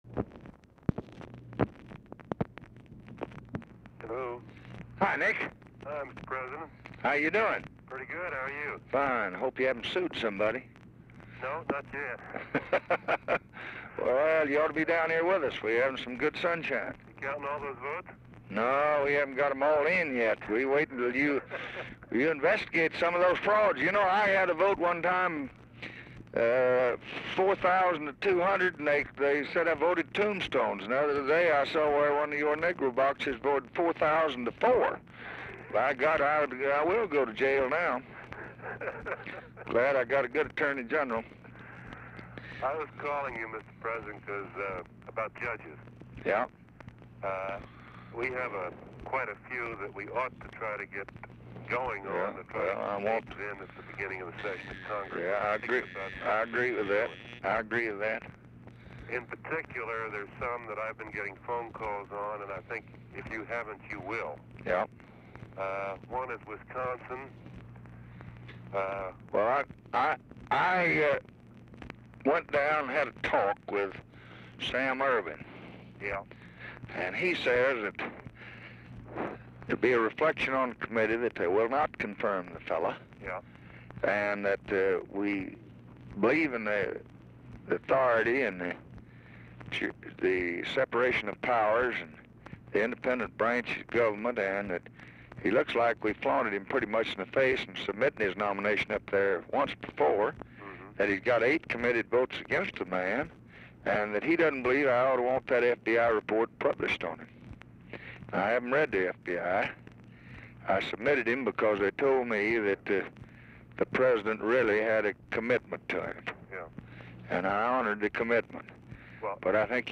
Telephone conversation # 6324, sound recording, LBJ and NICHOLAS KATZENBACH, 11/11/1964, 7:38PM | Discover LBJ
Format Dictation belt
Location Of Speaker 1 LBJ Ranch, near Stonewall, Texas
Specific Item Type Telephone conversation